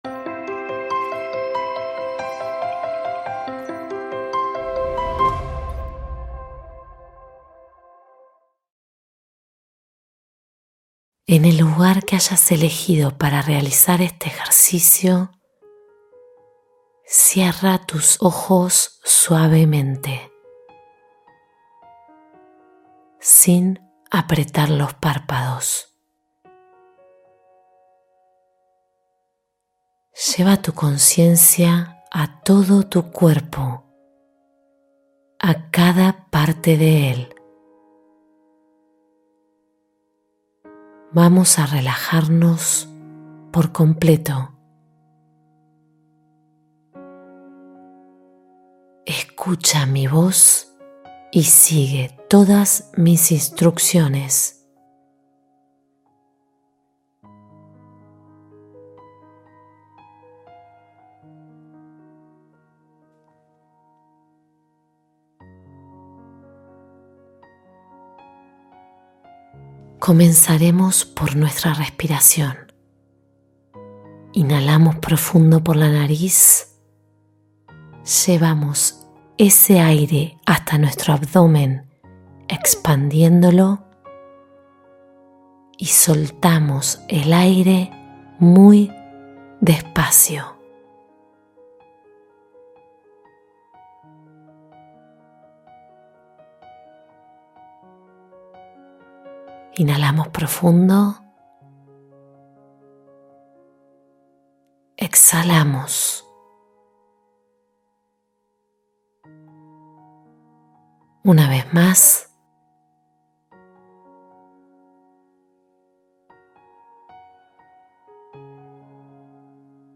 Soltar y Fluir: Meditación de Desapego